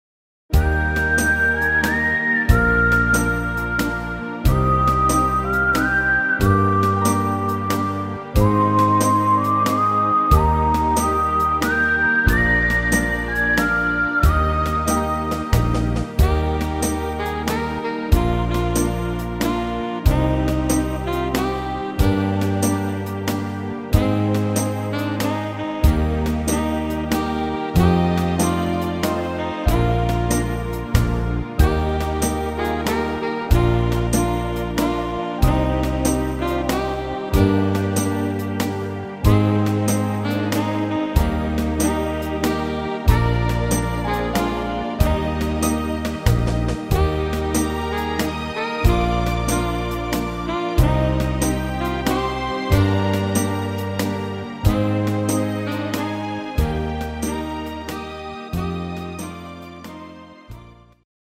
Rhythmus  Slowwaltz